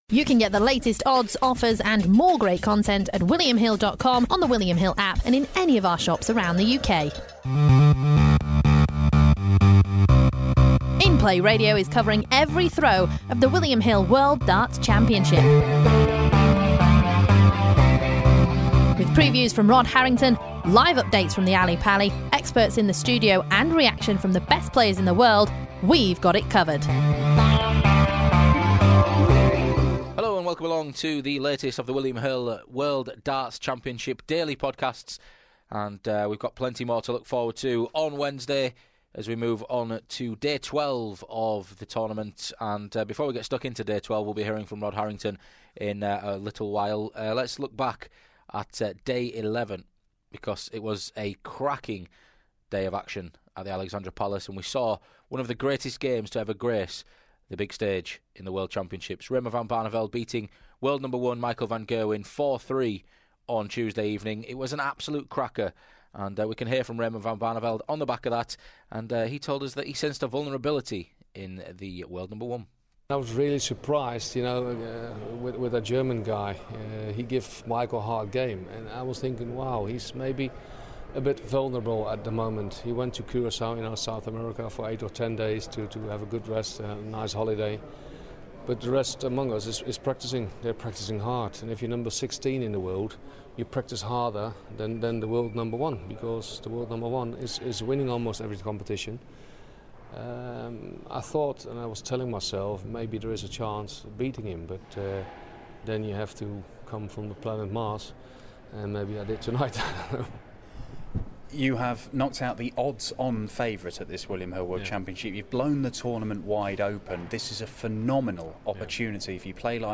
On this edition of the podcast we hear from Raymond van Barneveld and the other winners from Tuesday before looking ahead to day 12 with Rod Harrington's best bets for Wednesday.